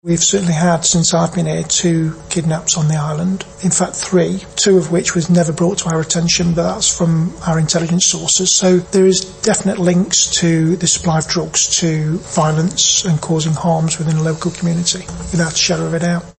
Chief Constable Russ Foster, giving evidence to the Tynwald Justice Committee, was asked about police efforts to get on top of the flow of drugs coming in.